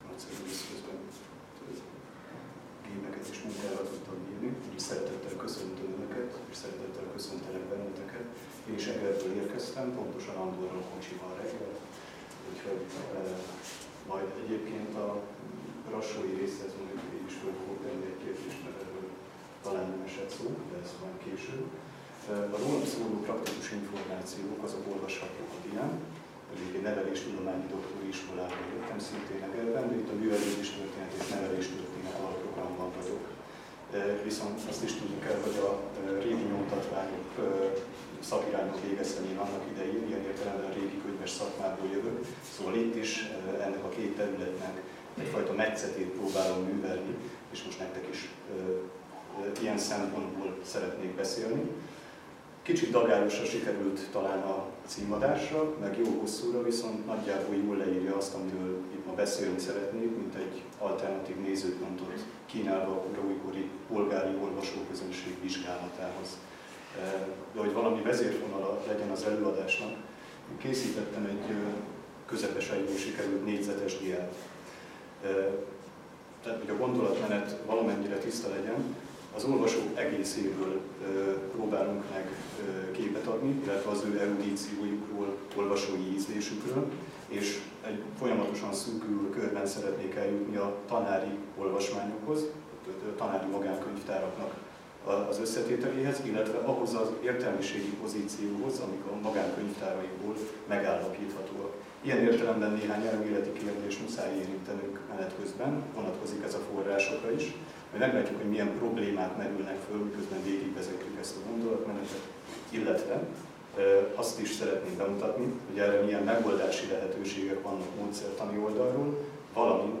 Szöveg, hordozó, közösség (Fiatalok Konferenciája 2015)